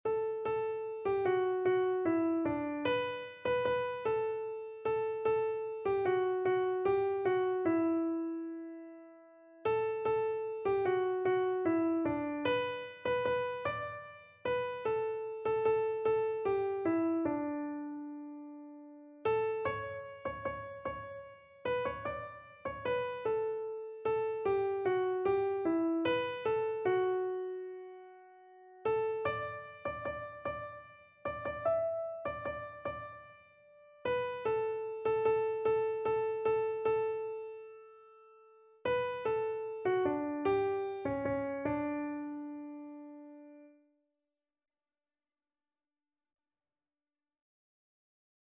Free Sheet music for Keyboard (Melody and Chords)
6/8 (View more 6/8 Music)
Classical (View more Classical Keyboard Music)